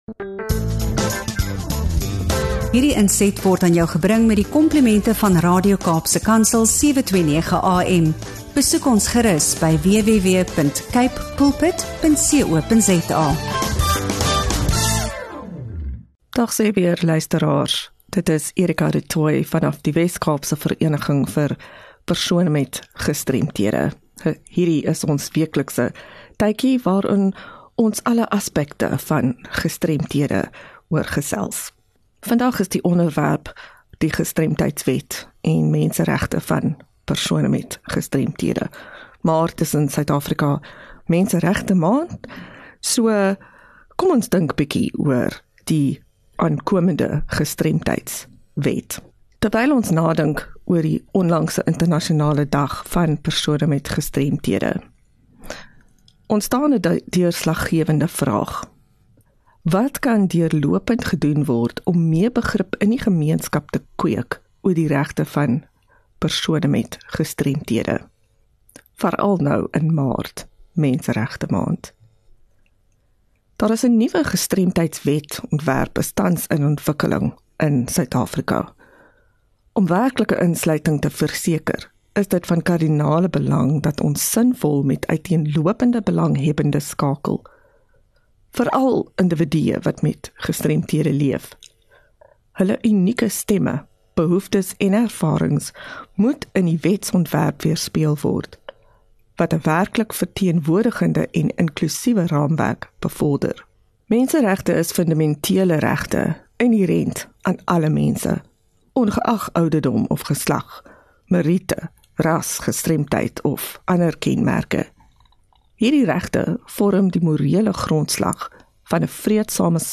25 Mar GEMEENSKAP GESTREMDHEID - 'n Geselskap oor Menseregte vir Persone met Gestremdhede